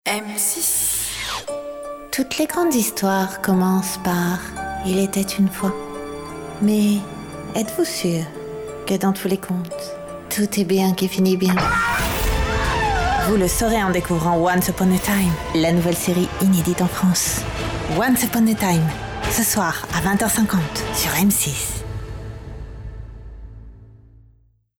Voix jeune et dynamique, teinte sensuelle
Sprechprobe: Werbung (Muttersprache):